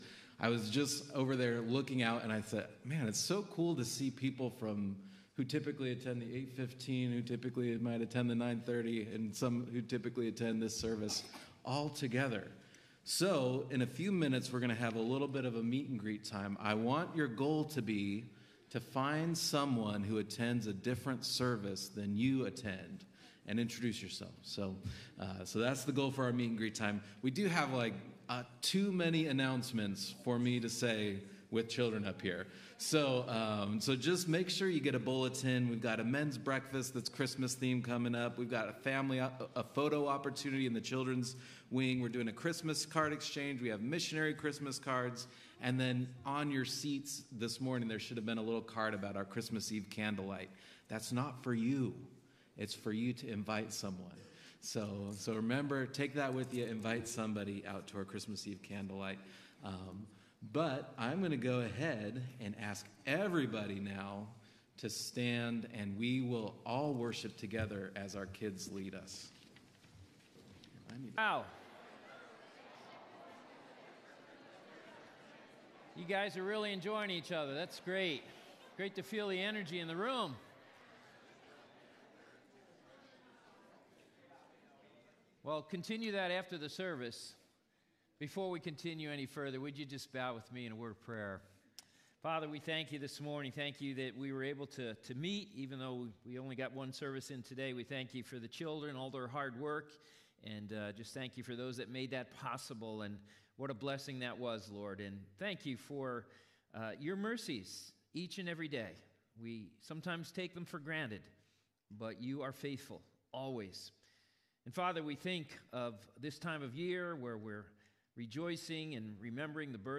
Sermons | Christian Fellowship Church